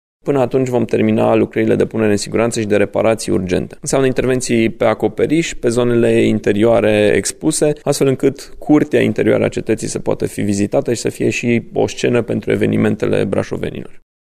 Municipalitatea are în plan să deruleze până atunci lucrări de punere în siguranță a obiectivului, după care monumentul și-ar putea redeschide porțile pentru turiști. Primarul municipiului Brașov, Allen Coliban.
COLIBAN-LUCRARI-CETATUIE.mp3